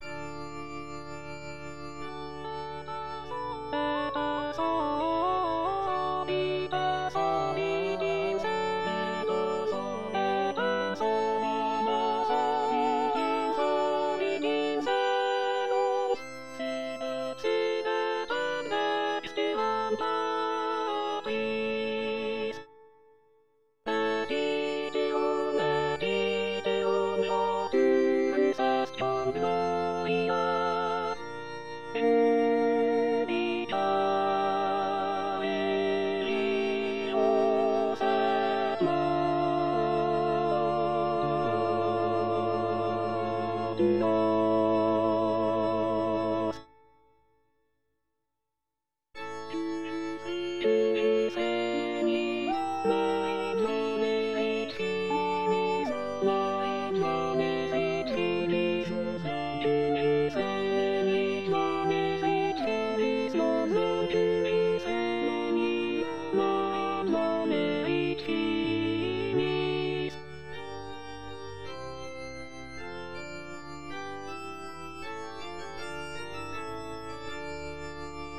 - On ne peut régler la balance entre la «voix en exergue» à droite, et les autres à gauche, qu'en utilisant les réglages de l’ordinateur.